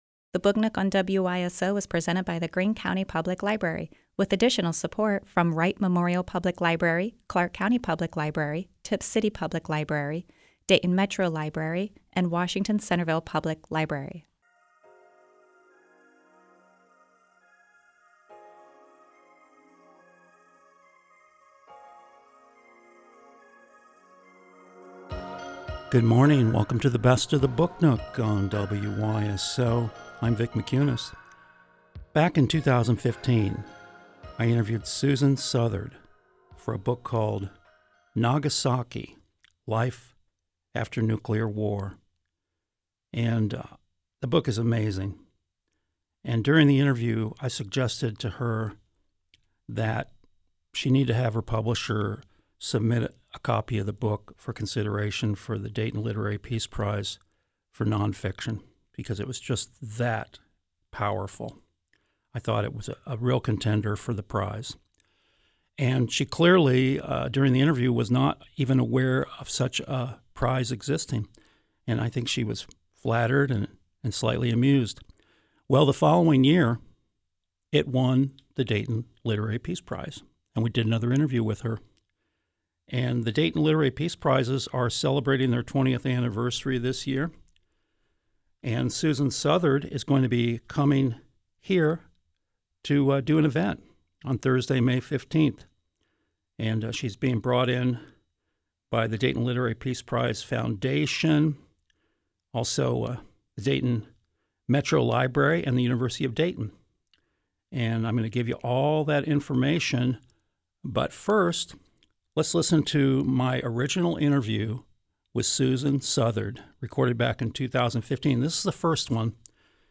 A fond remembrance of U.S. Postage stamps. Plus, an interview from the archives